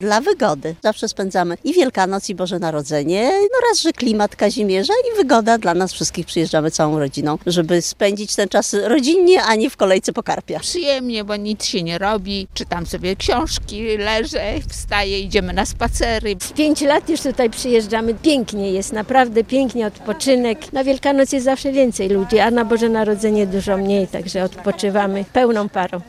mowia_turystki.mp3